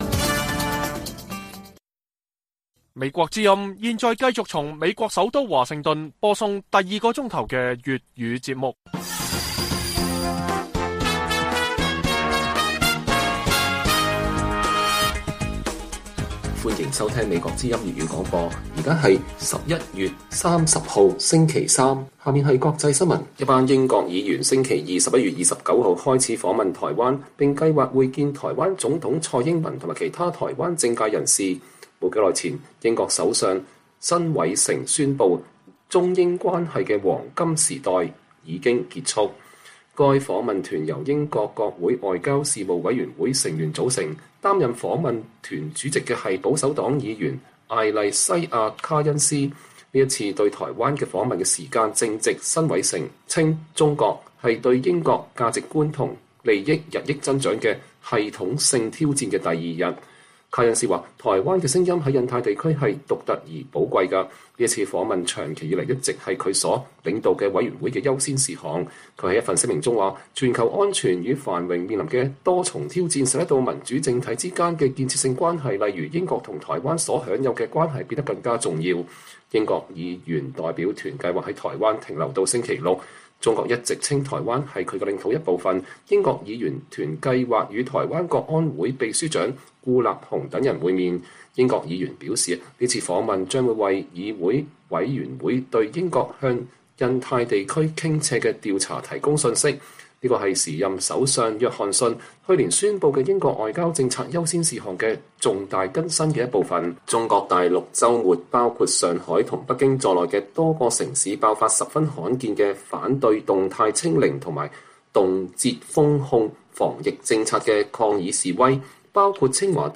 粵語新聞 晚上10-11點: 北京與倫敦關係緊張中，英國議員團訪問台灣